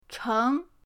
cheng2.mp3